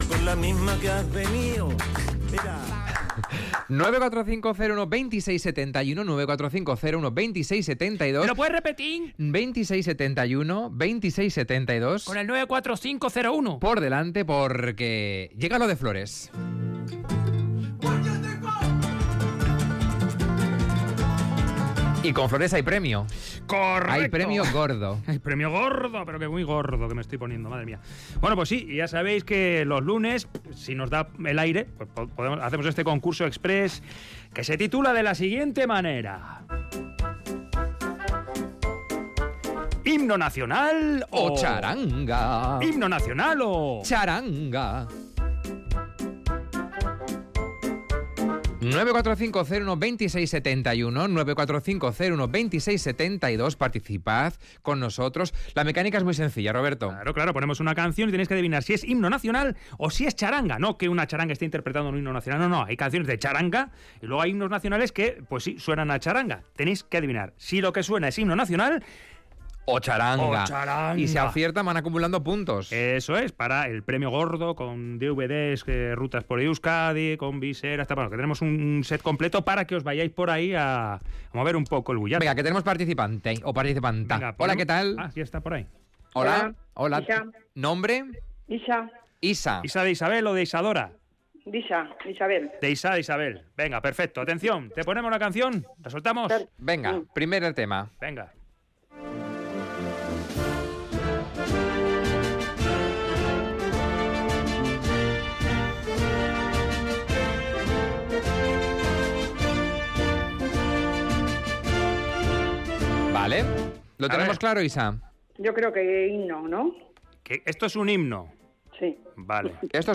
Si adivinas si lo que oyes es un Himno nacional o música de txaranga, acumulas puntos con premio. Pero, si arriesgas demasiado, lo pierdes todo.